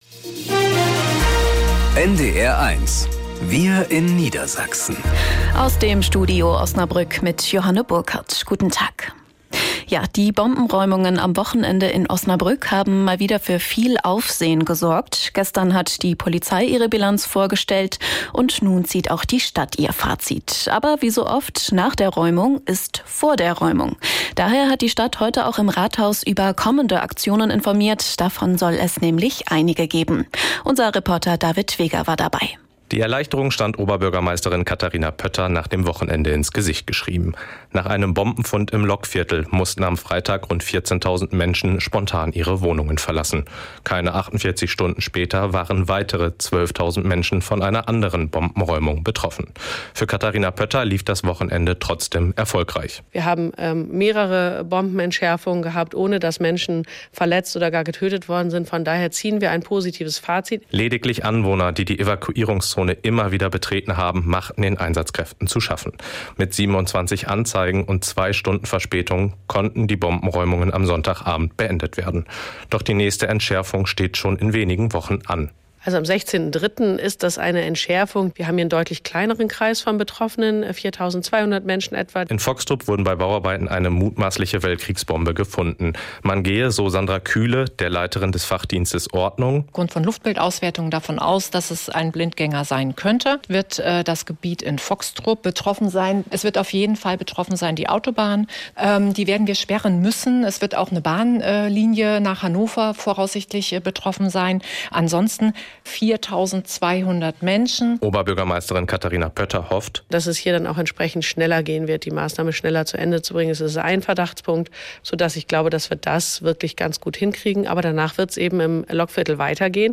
… continue reading 5 jakso # Tägliche Nachrichten # Nachrichten # NDR 1 Niedersachsen